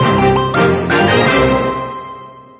На этой странице собраны звуки из игры Brawl Stars: голоса бойцов, звуки способностей, фразы при победе и поражении.